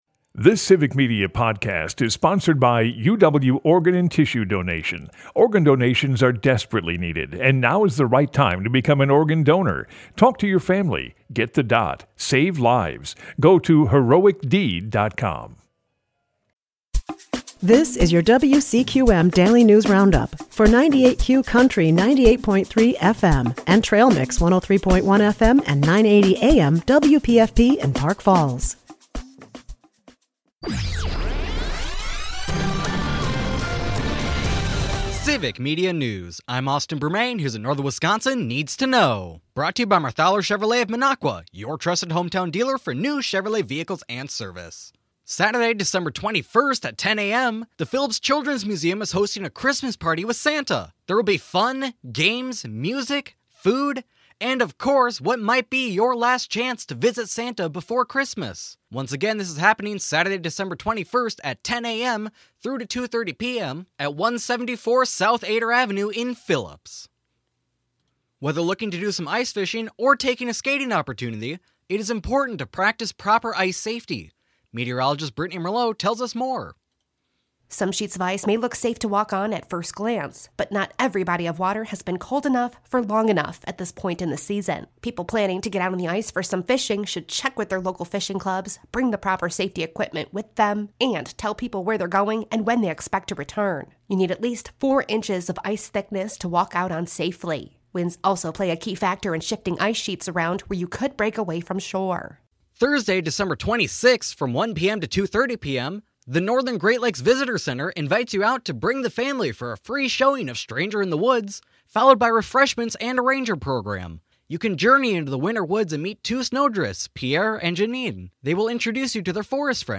98Q Country WCQM and WPFP have your state and local news, weather, and sports for Park Falls, delivered as a podcast every weekday.